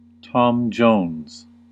Uttal
Synonymer lady-killer casanova Lothario Don Juan Uttal US UK: IPA : /ˌtɒm ˈdʒəʊnz/ US: IPA : /ˌtɑm ˈdʒoʊnz/ Ordet hittades på dessa språk: engelska Ingen översättning hittades i den valda målspråket.